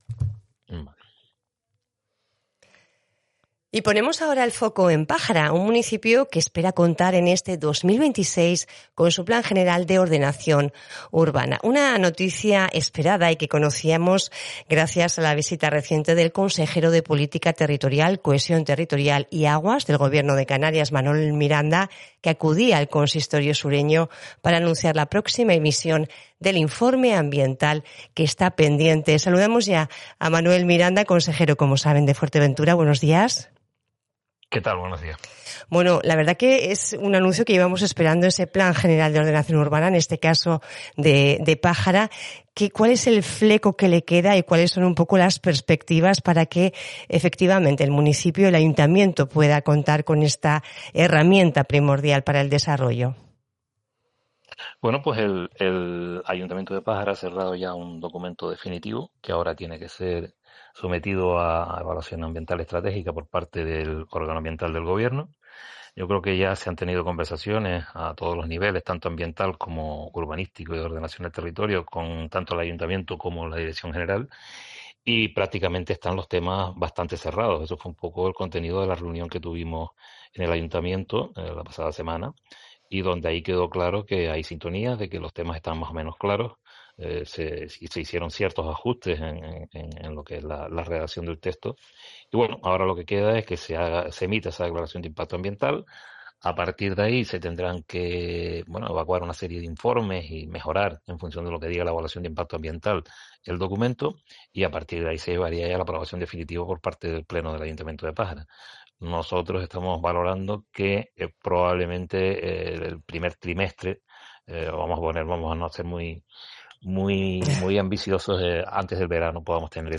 Así lo explicó el consejero de Política Territorial, Cohesión Territorial y Aguas del Ejecutivo autonómico, Manuel Miranda, en una entrevista concedida a La Voz de Fuerteventura en Radio Insular.